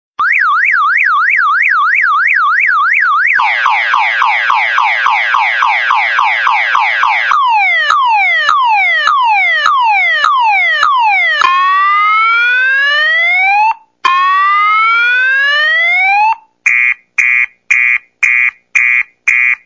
Alarm ringtone download
File Name: Alarm.mp3